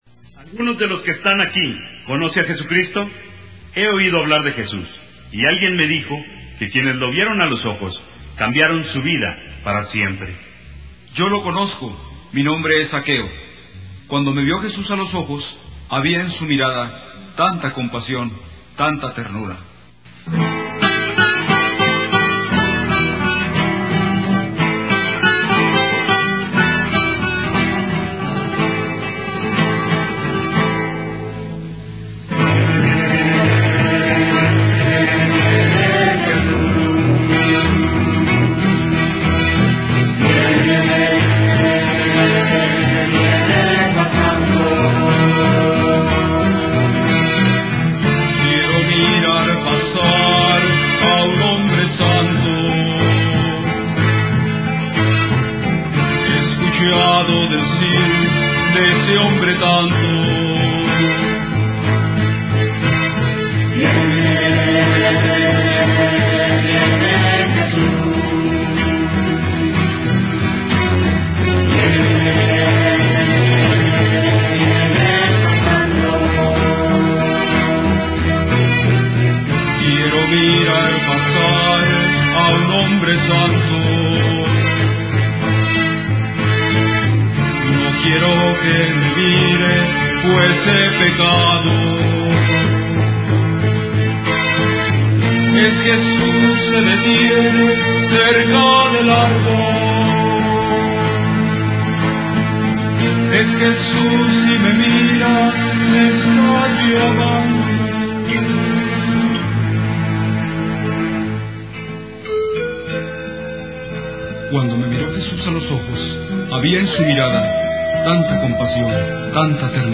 (Contralto)
(Tenor)